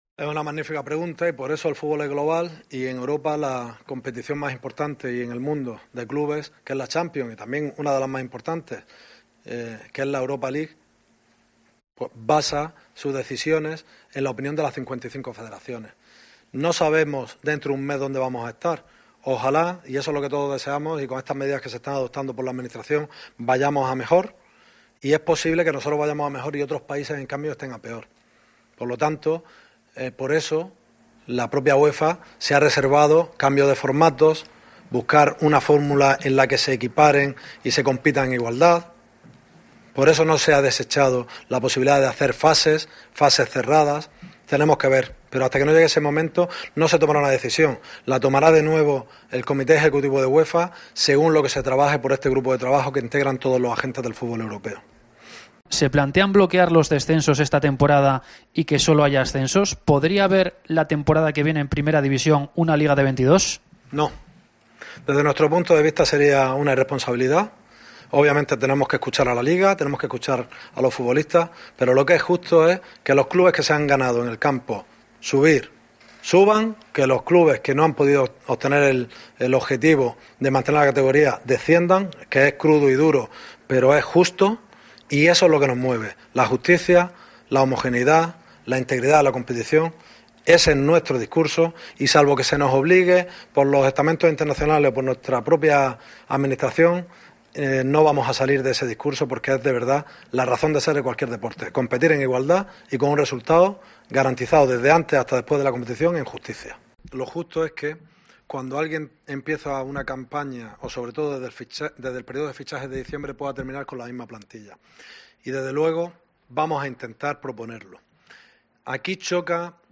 El presidente de la Federacion Española analiza la situación actual del fútbl